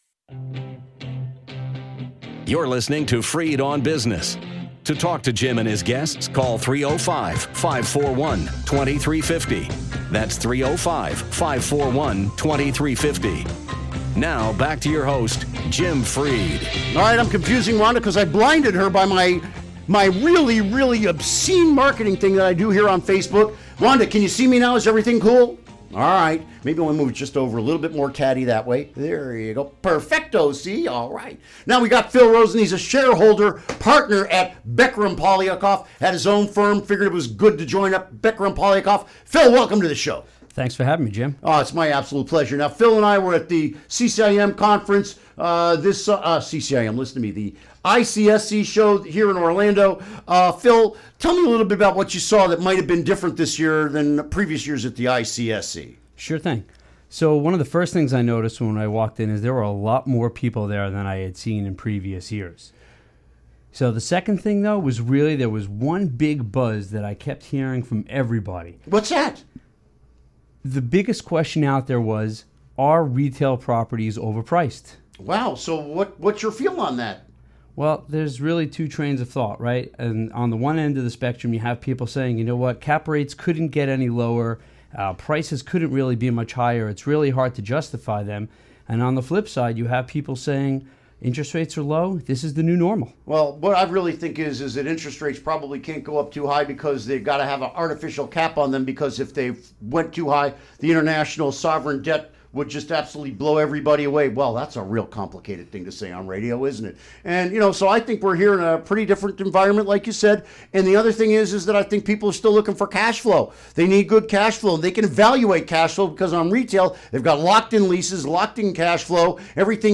Interview Segment Episode 383: 08-25-16 Download Now!